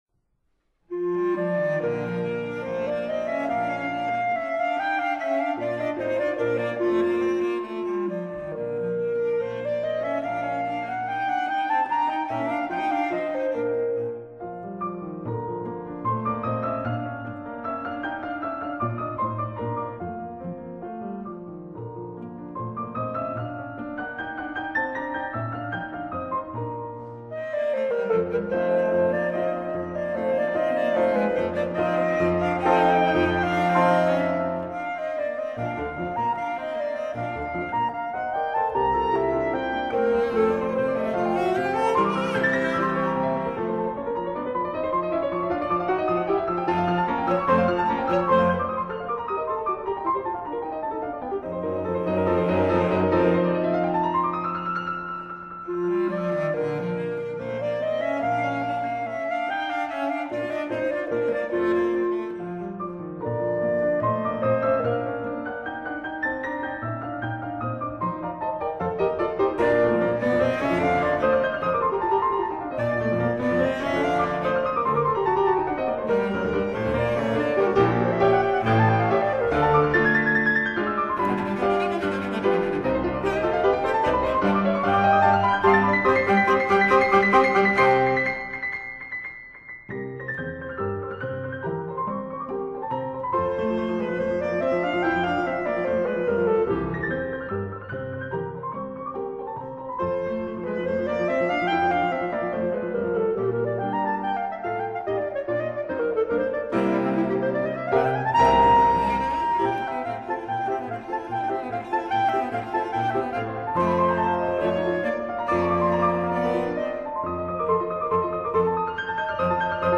(1-4 G小調為豎笛、鋼琴與大提琴的三重奏Op.28)